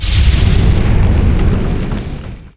attack2_3.wav